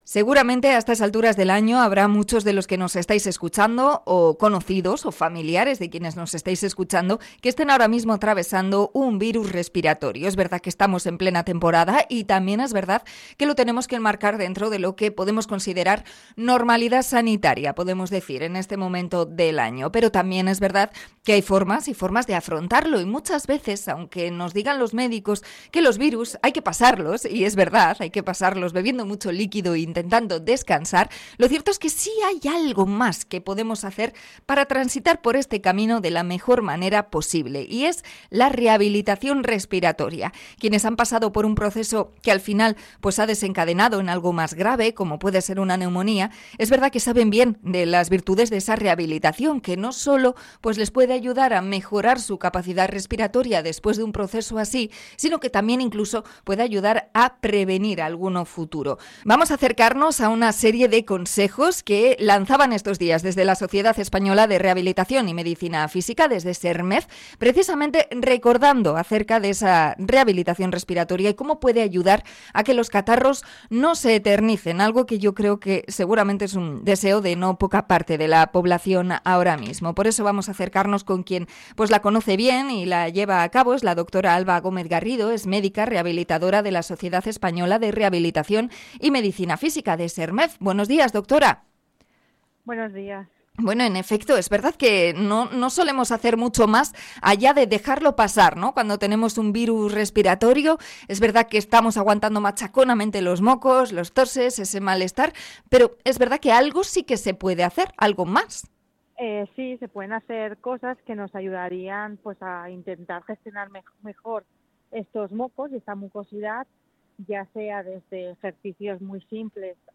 Entrevista a rehabilitadora respiratoria